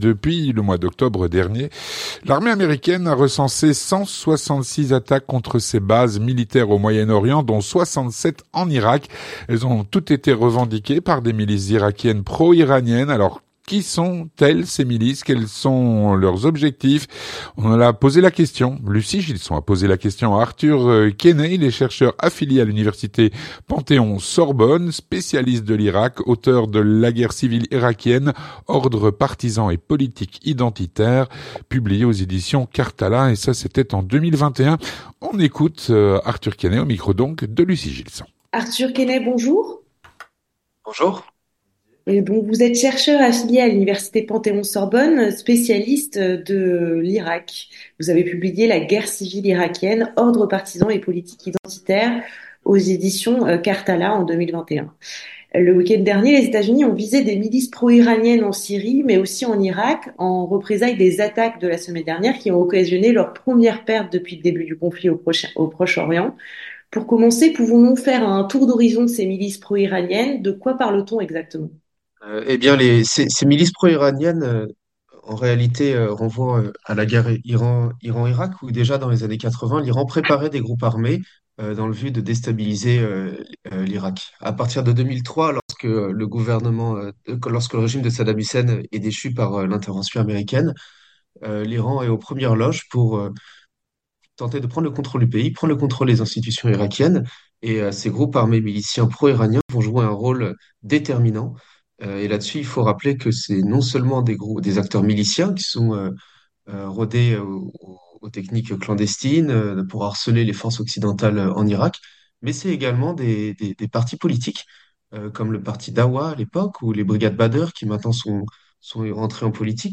L'entretien du 18H - Depuis octobre dernier, l’armée américaine a recensé 166 attaques contre ses bases au Moyen-Orient, dont 67 en Irak.